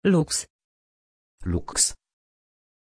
Aussprache von Lux
pronunciation-lux-pl.mp3